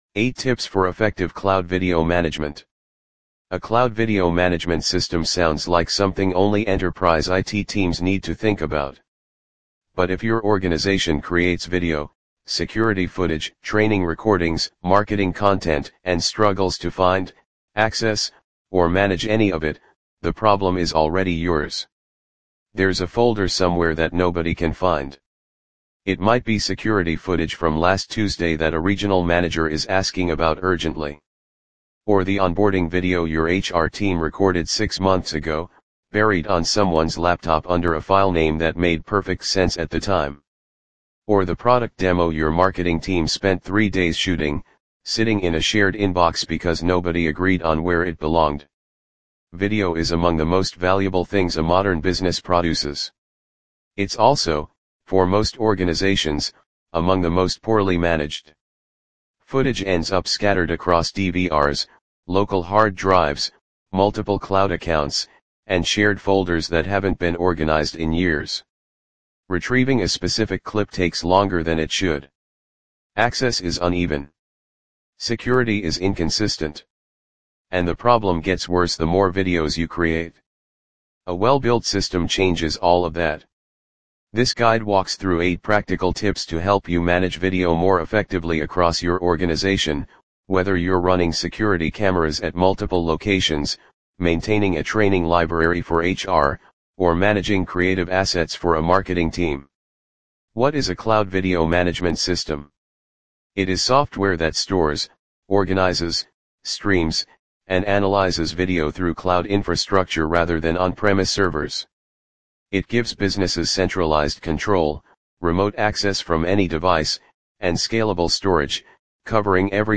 Read Aloud!